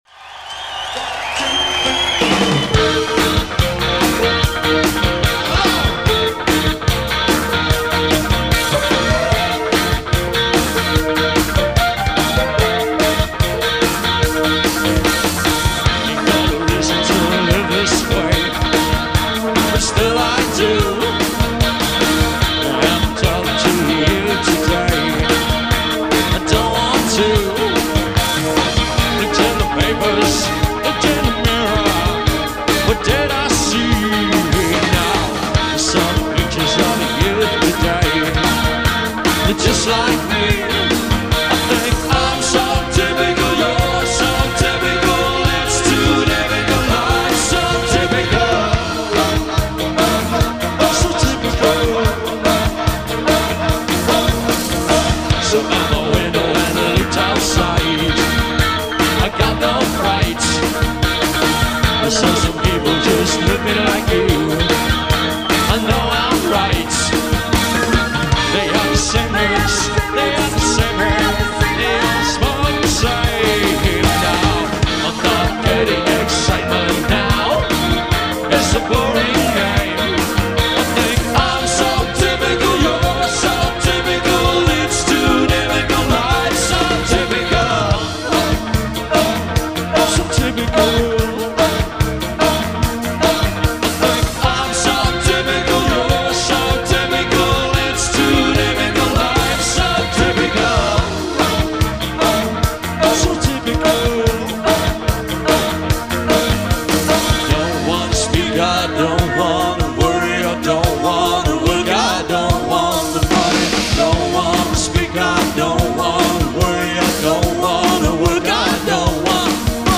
New Wave
keyboard